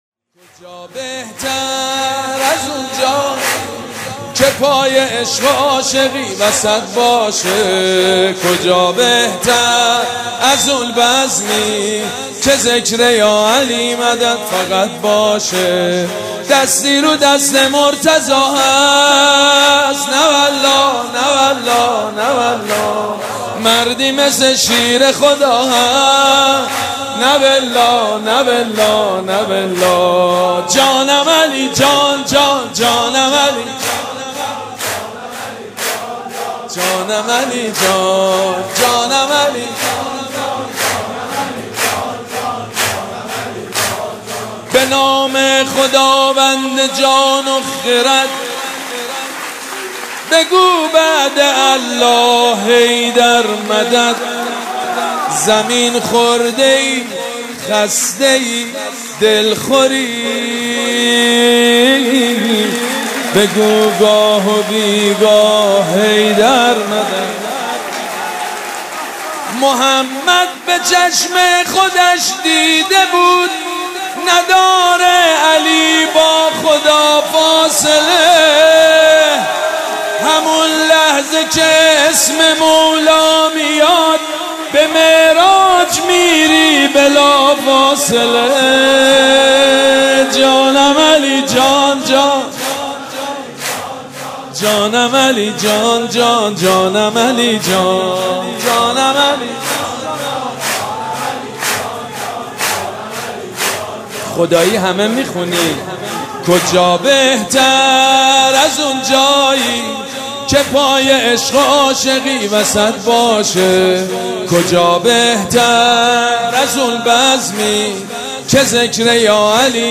مراسم جشن ولادت امیرالمومنین(ع)
سرود